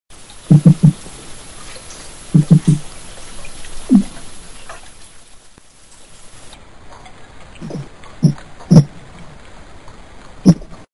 Sounds Made by Silurus glanis
Type of sound produced thumps, knocks, escape sounds
Sound production organ pneumatic duct & swim bladder
Sound mechanism not known but probably vibration of swim bladder & pneumatic duct caused by quick contraction of associated muscles
Behavioural context spontaneous nocturnal sound production
Remark outdoor recording late at night (freshwater lake). Sound production by 2 different specimen. 2 sound sequences and some single sounds. Sounds from different individuals were separated by audio technical fade-in and fade-out.
Amplified by 4 dB during processing of sound file